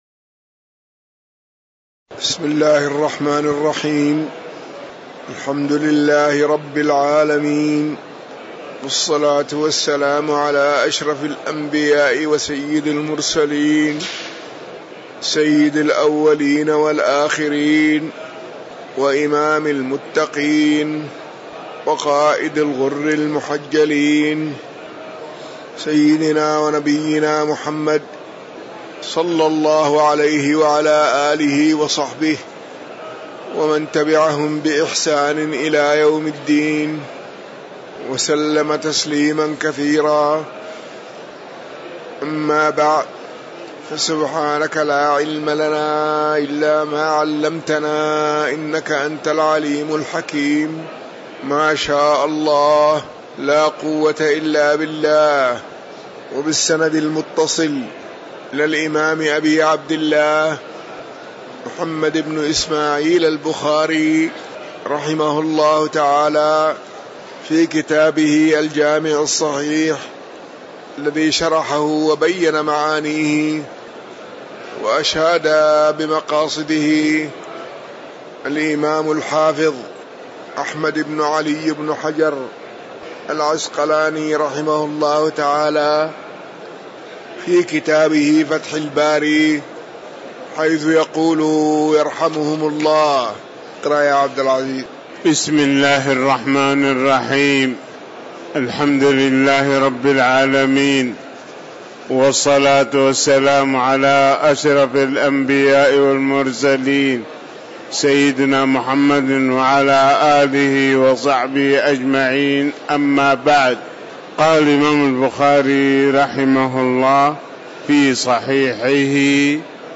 تاريخ النشر ٥ محرم ١٤٤١ هـ المكان: المسجد النبوي الشيخ